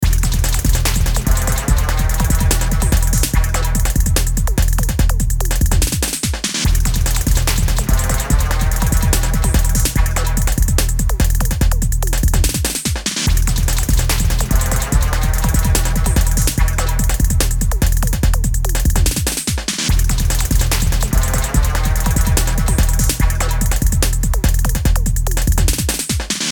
カラフルで幻覚的なサウンドがループする、サイケデリックなトランス。夢幻的なサウンドスケープ。無限に続くループ。